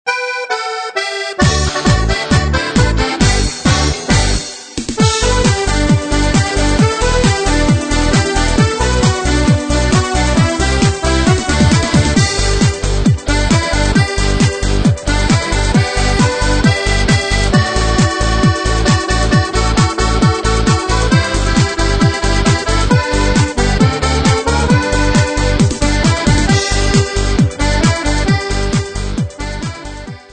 Besetzung: Akkordeon